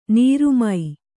♪ nīru mai